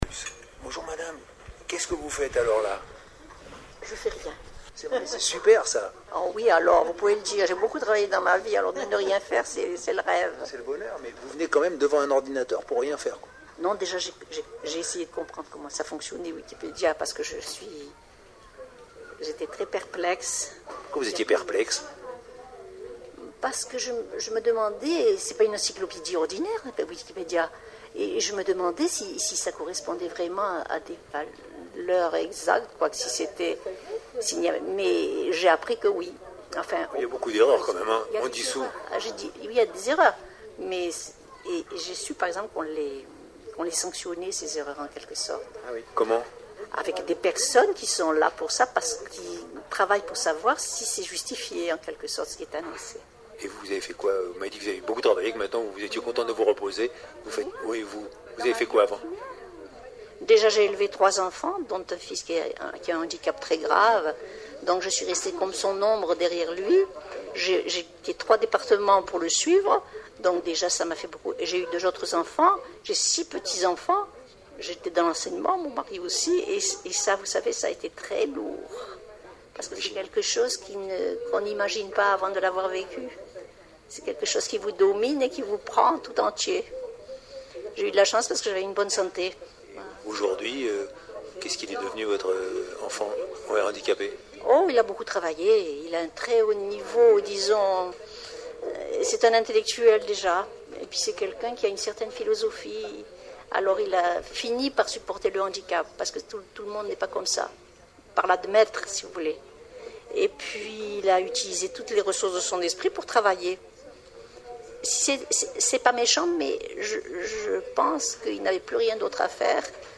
Pour l’énergie de cette vieille dame, pour la façon si simple et digne dont elle parle de cette longue vie qu’on sent parsemée d’épreuves, pour sa curiosité encore, pour ses valeurs, pour son côté « les pieds sur terre ».
Et aussi parce que cette conversation se passe dans une bibliothèque de quartier et j’aime les bibliothèques depuis toujours.